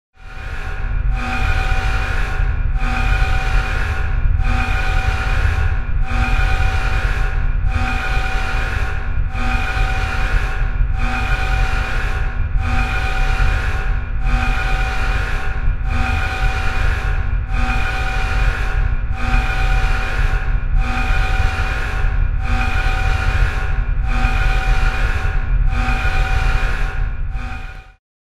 Звуки сигналов тревоги
Тревога на борту космического корабля